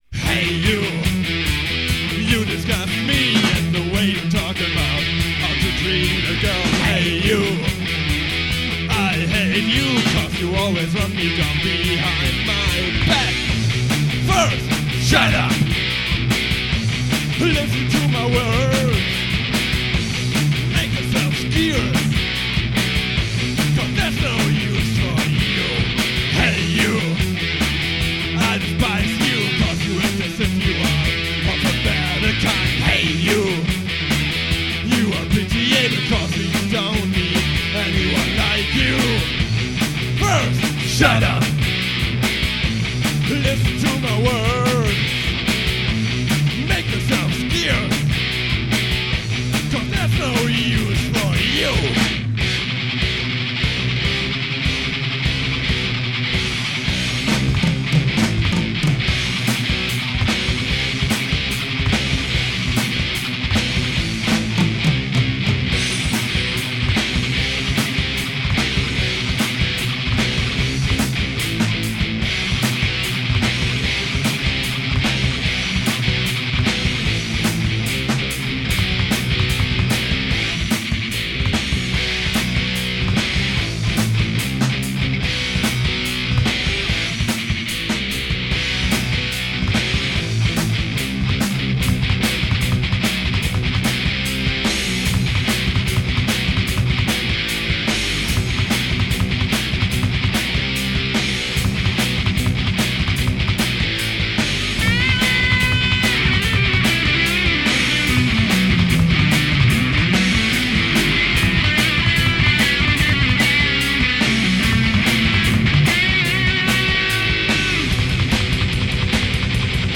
[Demo Tape 1994]
vocals, guitar, bass
drums